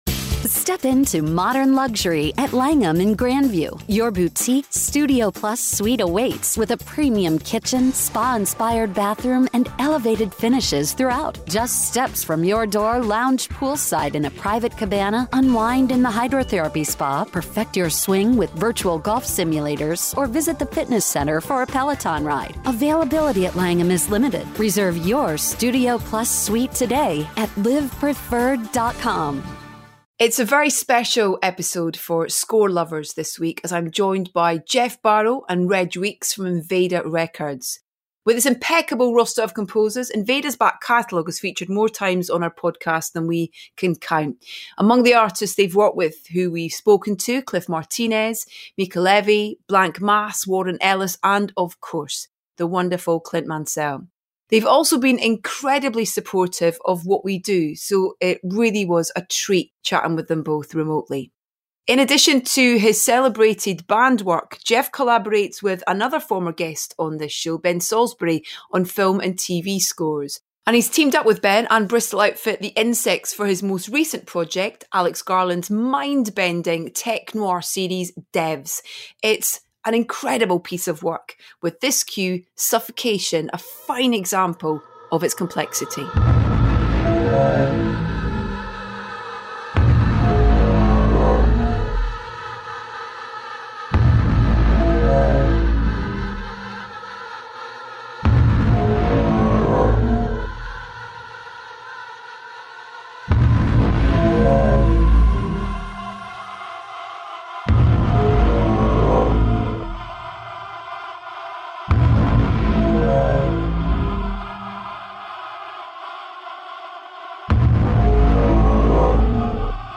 They've also been incredibly supportive of what we do, so it really was a treat chatting with them both remotely.
As always, there's plenty of music to enjoy.